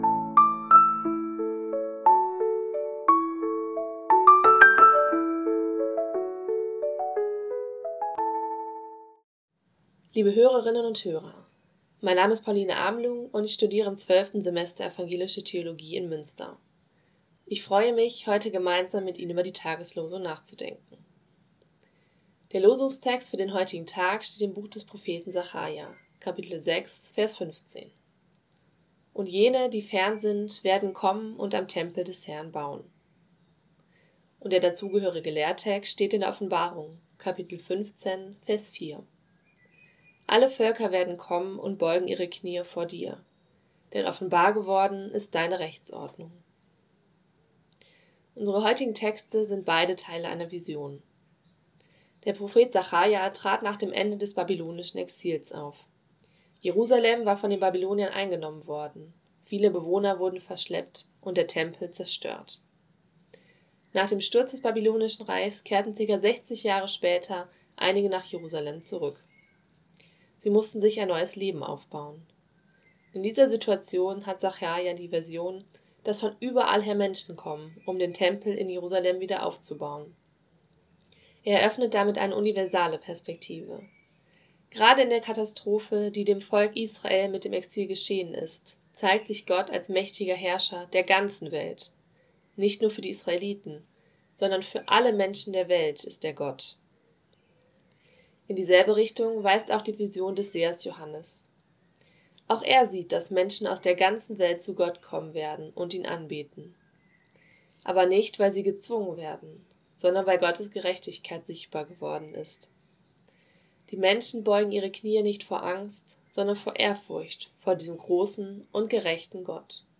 Losungsandacht für Mittwoch, 25.06.2025